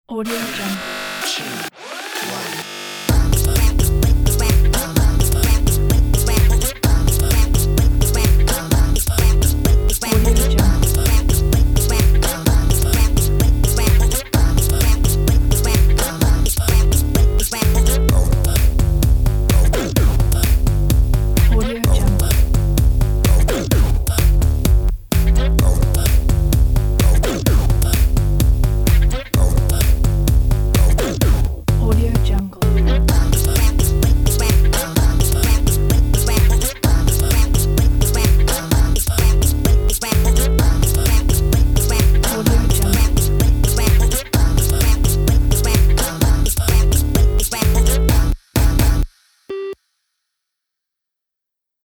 درام، باس، ریتمیک